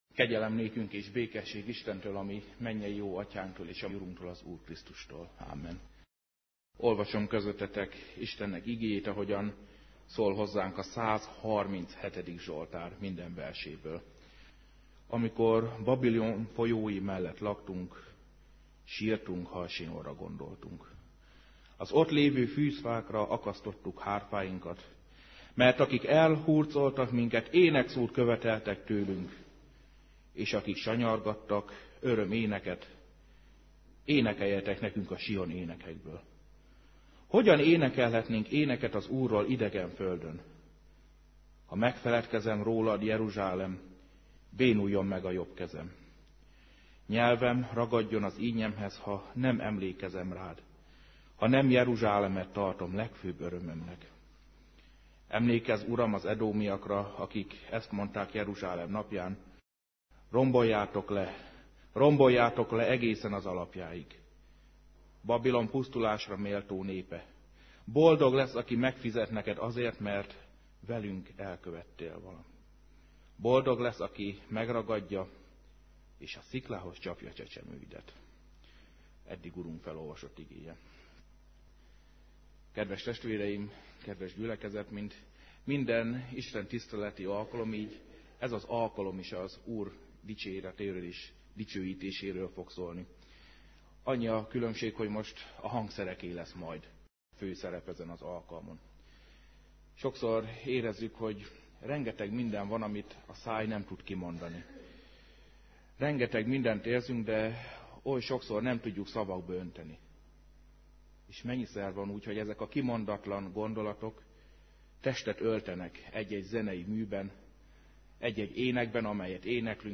2011 december 04, vasárnap délután; Adventi zenés áhitat.